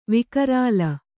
♪ vikarāla